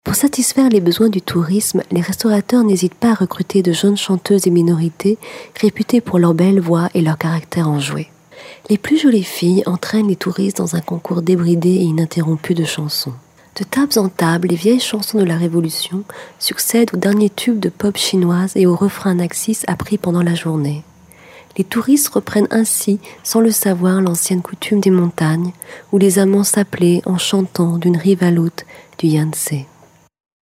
extrait de voix 3
Comédienne
- Mezzo-soprano